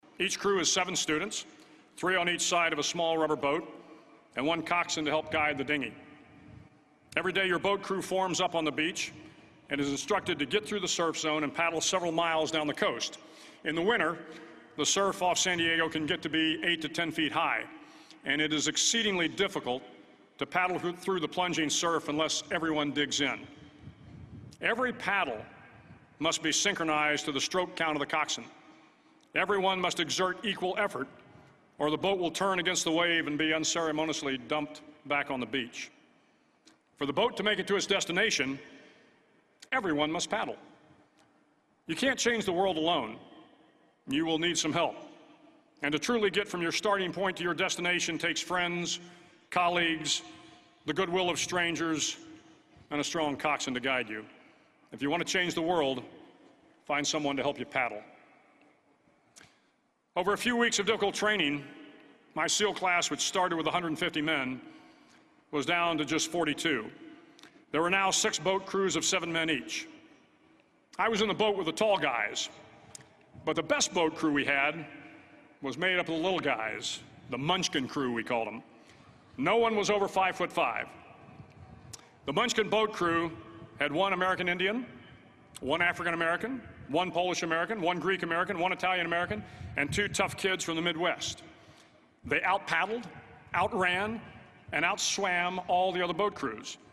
公众人物毕业演讲 第232期:威廉麦克雷文2014德州大学演讲(5) 听力文件下载—在线英语听力室